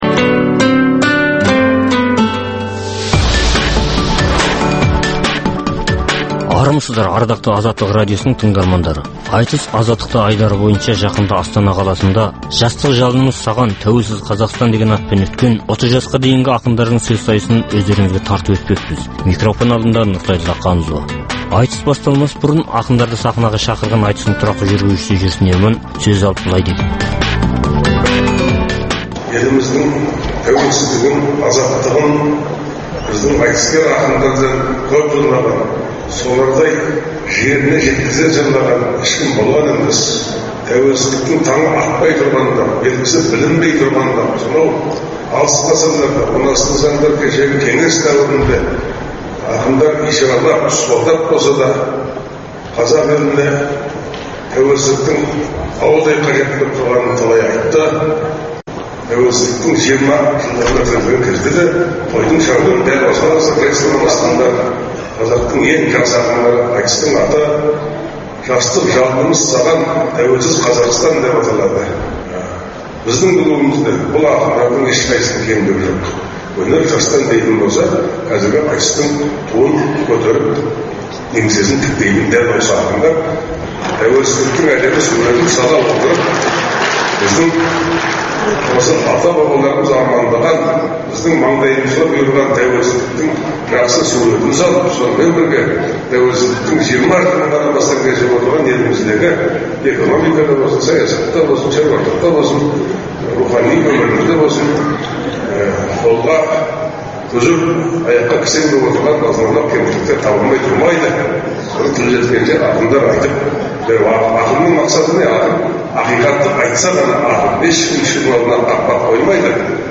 Айтыс - Азаттықта